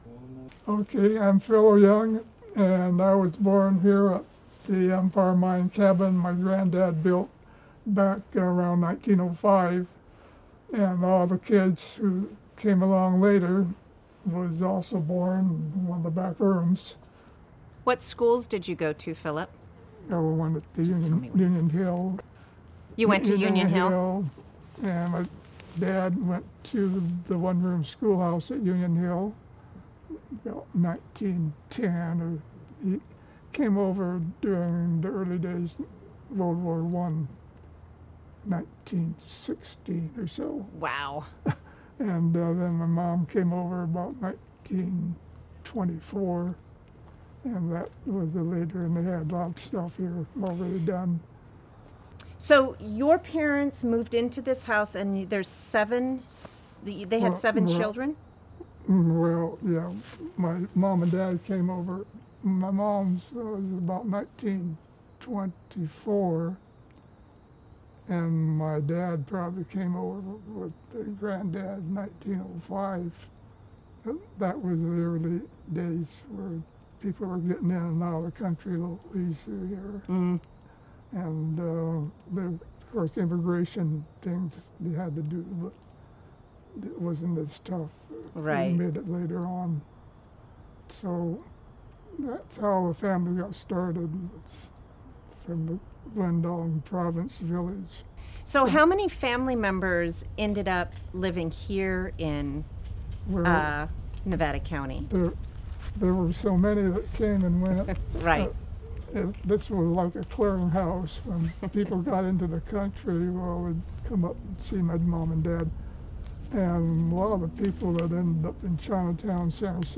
A portion of the interview